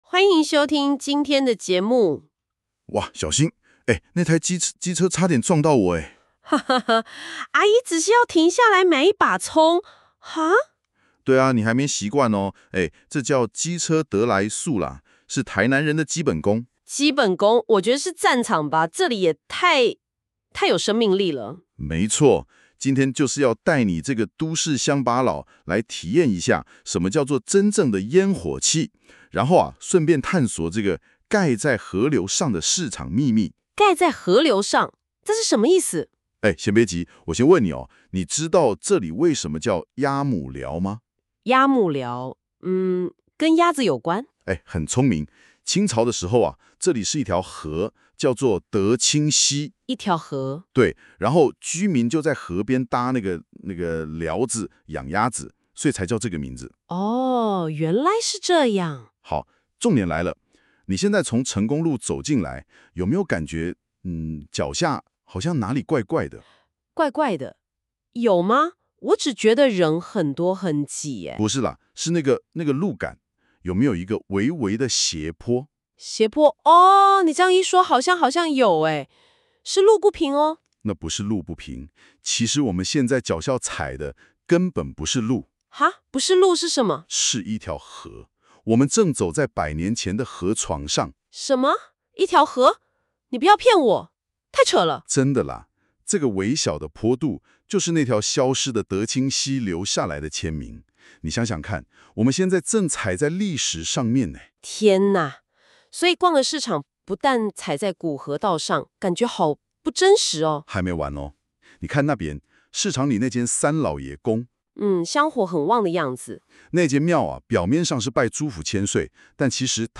這集節目收錄了編輯部對於「垃圾麵」與「巨無霸布丁」最真實的驚嘆與激辯。